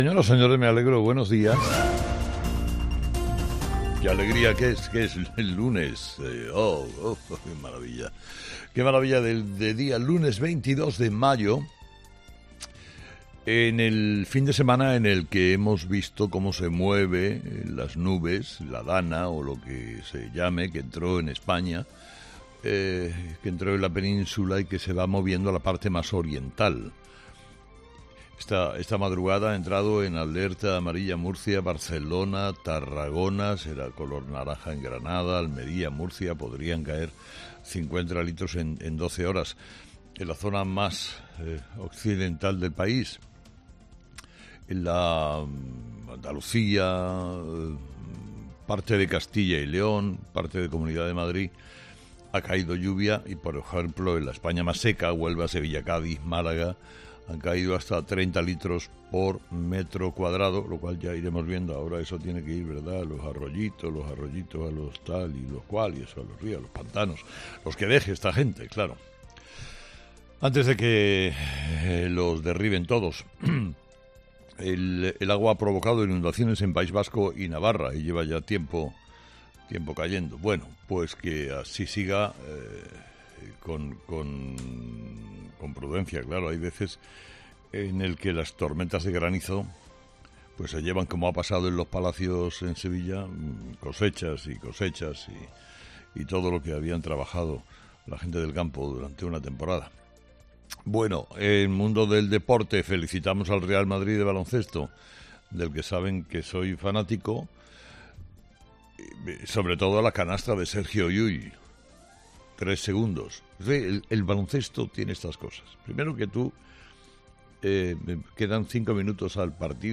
AUDIO: Carlos Herrera repasa los principales titulares que marcarán la actualidad de este lunes 22 de mayo en nuestro país